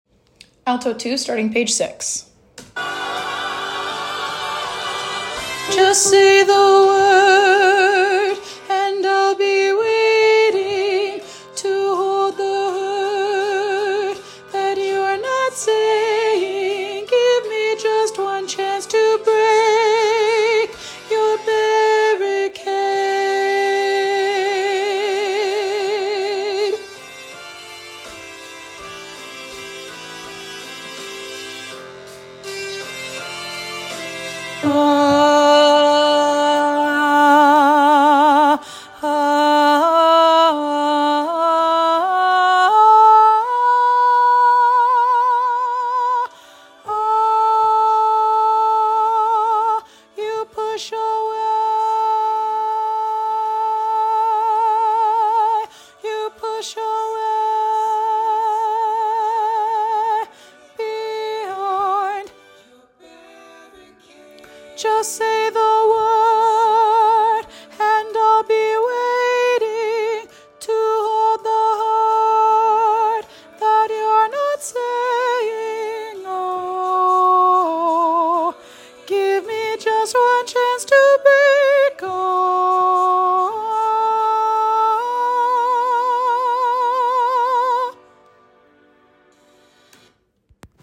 with lush harmonies, tall chords, and an epic conclusion.
Alto 2